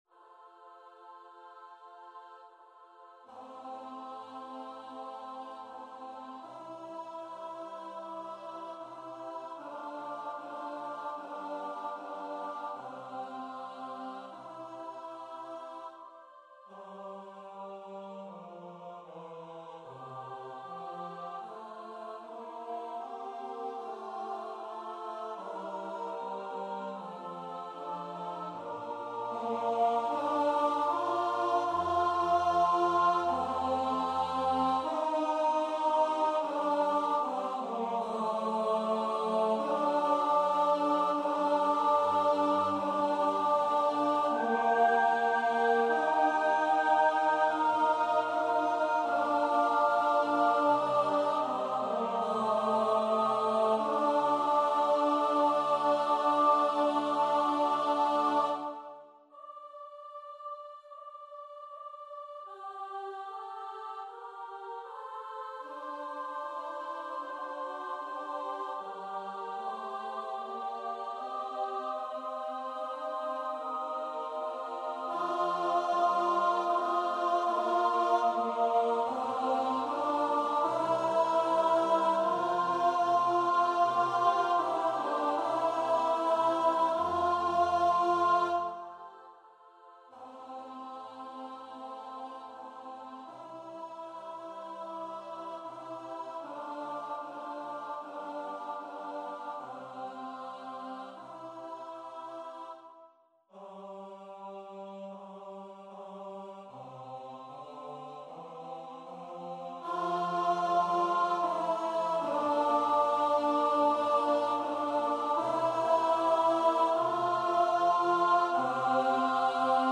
Abendlied-Tenor1-Contra-Alto.mp3